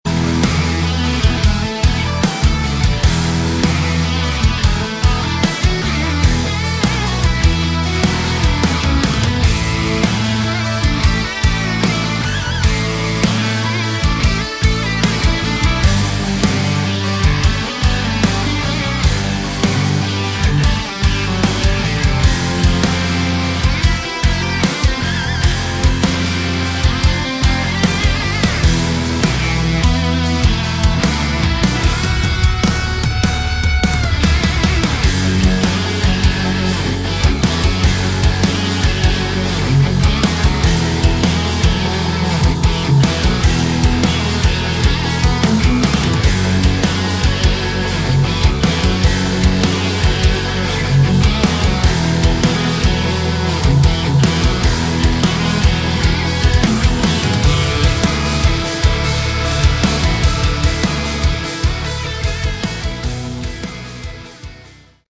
Category: Melodic Metal / Prog Metal
vocals
guitars
bass
drums
keyboards